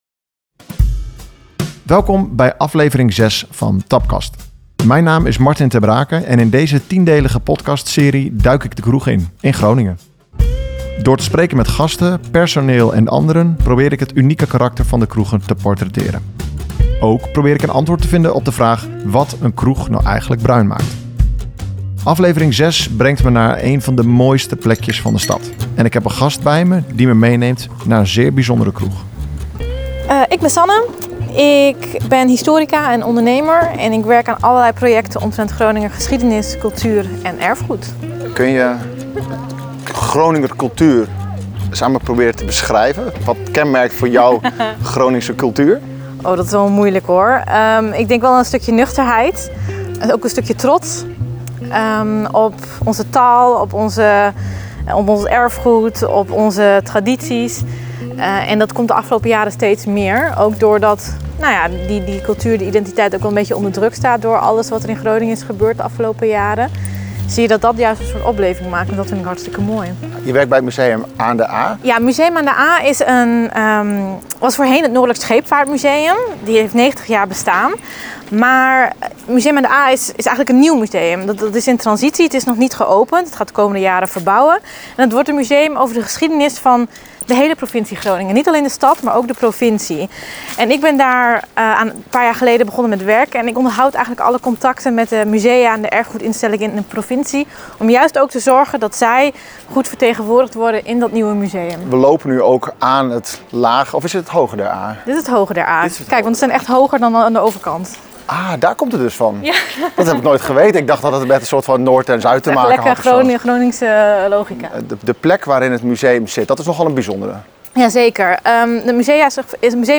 Het decor van het gesprek is café De Sleutel.